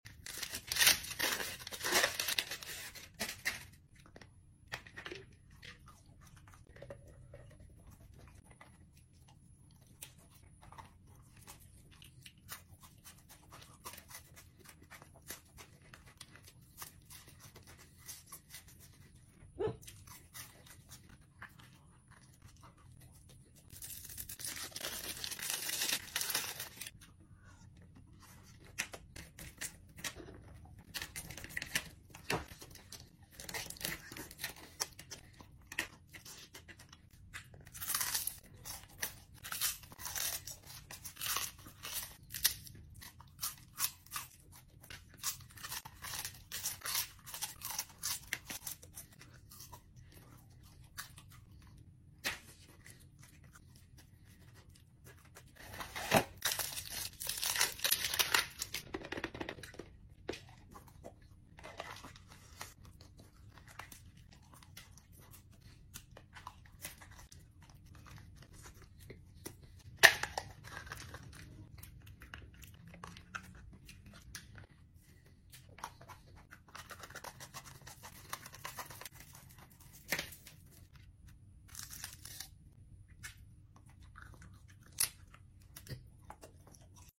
Food ASMR Eating a Chocolate sound effects free download
Food ASMR Eating a Chocolate Egg